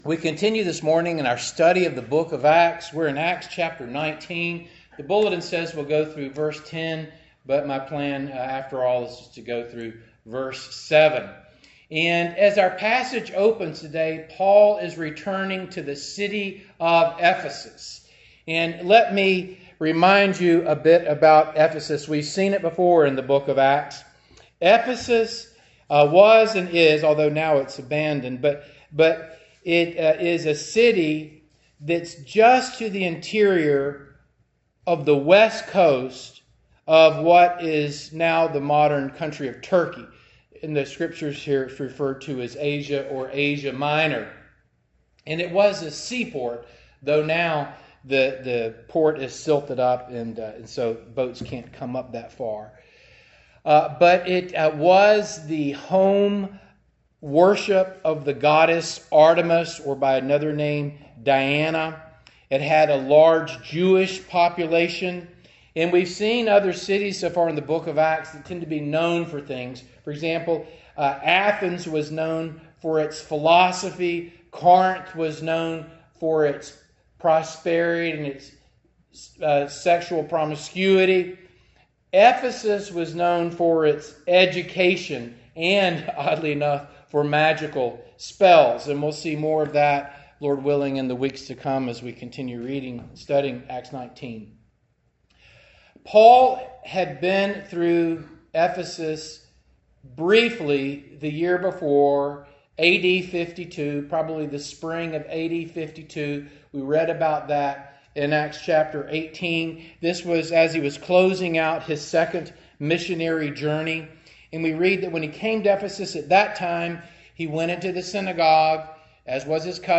Passage: Acts 19:1-7 Service Type: Morning Service